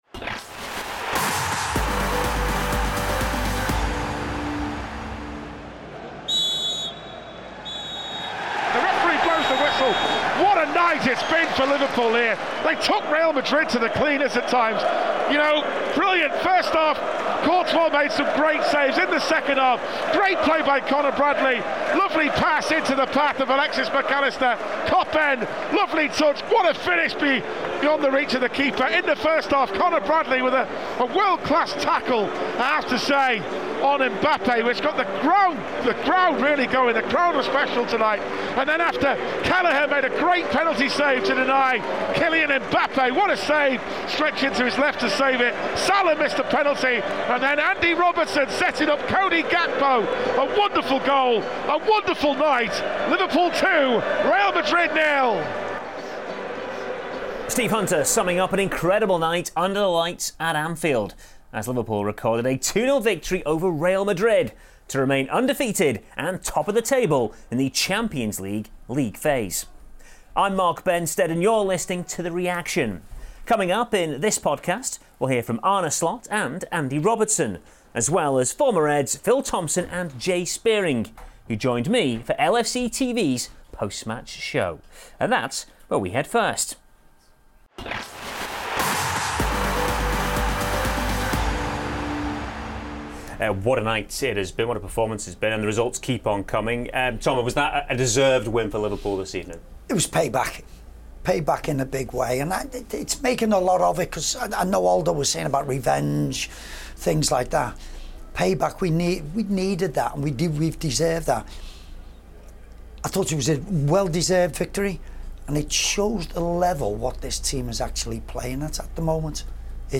Coming up in this podcast we'll hear from Arne Slot and Andy Robertson as the Reds produced yet another performance of class and authority to deservedly defeat the holders and reel off a fifth consecutive victory in the competition’s league phase.